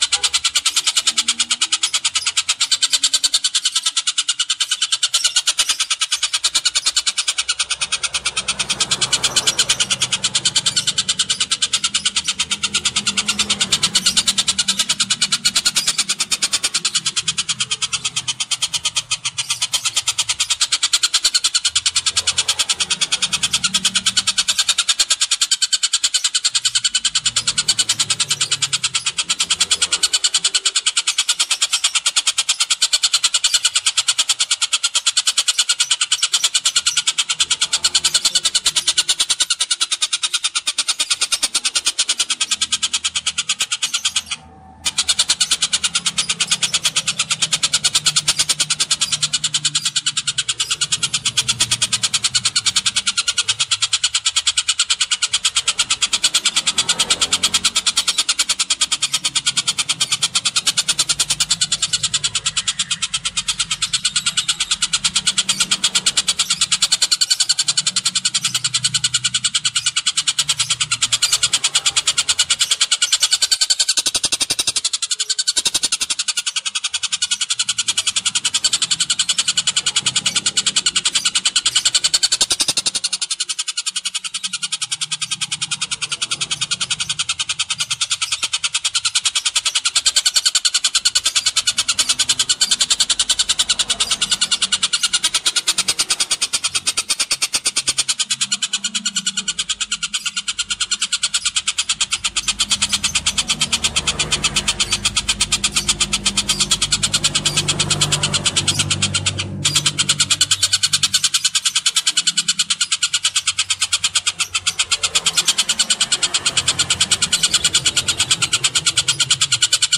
Suara Tengkek Buto Ngekek Panjang
Kategori: Suara burung
Keterangan: Download suara masteran tengkek buto durasi panjang dengan kualitas suara jernih dan gacor. Suara ngekek panjang rapat, cocok untuk latihan burung agar lebih gacor.
suara-burung-tengkek-buto-ngekek-panjang-id-www_tiengdong_com.mp3